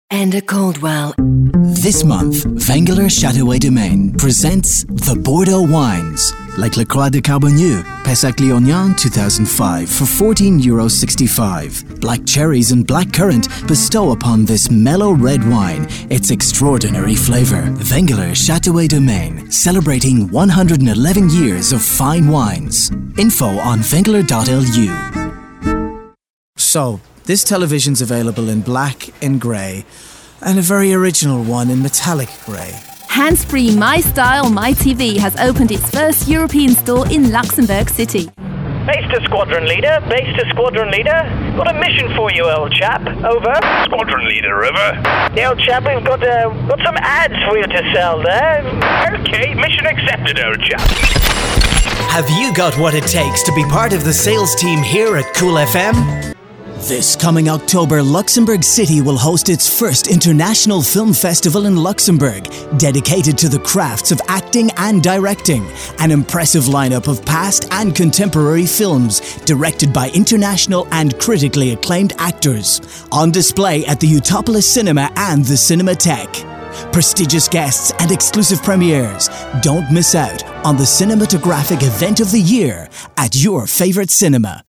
Irish Voice Over, Fresh, Engaging, Clear, A voice that cuts right through, Male, Young, Middle, Irish, Ireland, UK, English, European, Different, Specialist, Radio Imaging, Commercial Reads, Advertising, Natural, Warm, Flow, Popular, Bubbly. Available for Narration projects, Commercial Reads, Radio Imaging, Television Commercial Spots & Promos and Imaging, Cinematic Voice Overs, Film, On Hold and Corporate Voice Overs. British accents, Character voices, Acting and more!
irisch
Sprechprobe: Werbung (Muttersprache):
Irish worldwide voice artist